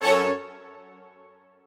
strings5_18.ogg